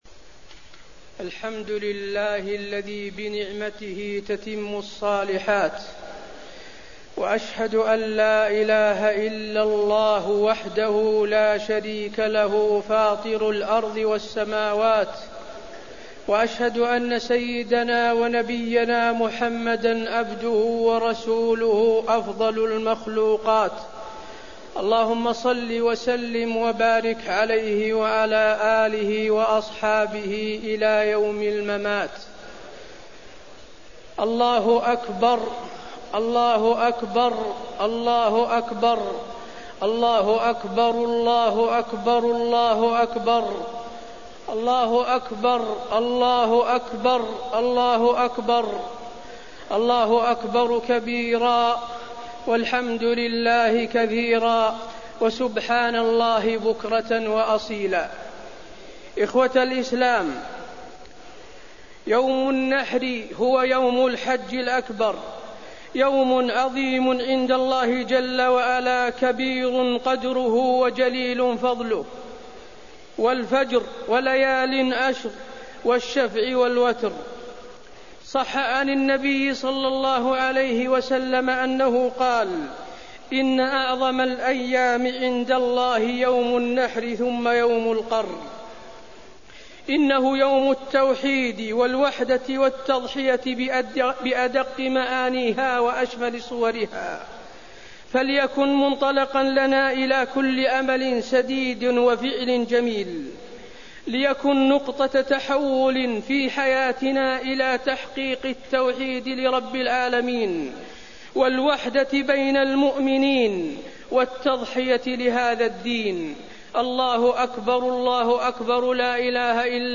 خطبة عيد الأضحى - المدينة - الشيخ حسين آل الشيخ
المكان: المسجد النبوي